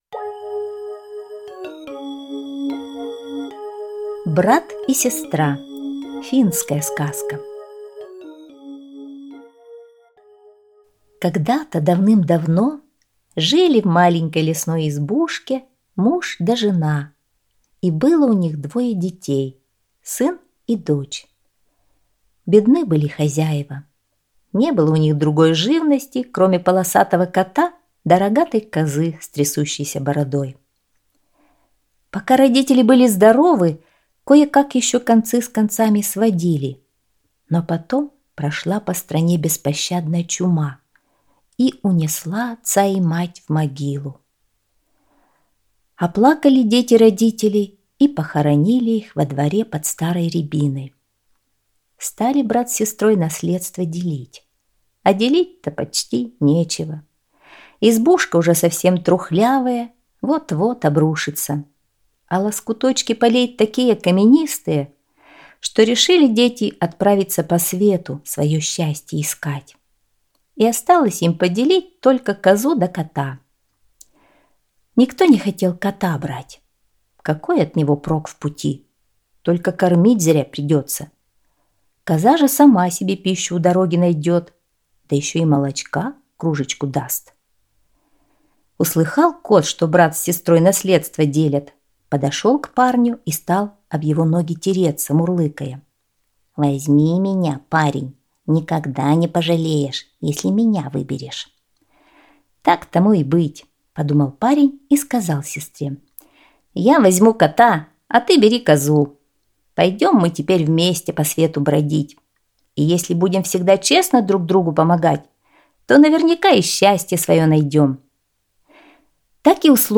Брат и сестра - финская аудиосказка - слушать скачать